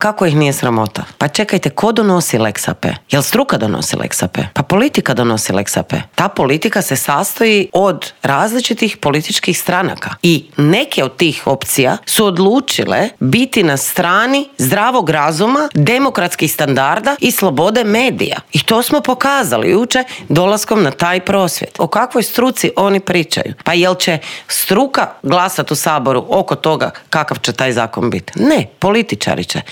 ZAGREB - U Intervjuu tjedna Media servisa gostovala je saborska zastupnica i premijerska kandidatkinja stranke Možemo Sandra Benčić, koja je prokomentirala nove izmjene koje je Vlada najavila uvrstiti u konačni prijedlog tzv. Lex AP-a, osvrnula se na reakciju vladajućih na jučerašnji prosvjed HND-a zbog kaznenog djela o curenju informacija, ali i na kandidata za glavnog državnog odvjetnika Ivana Turudića.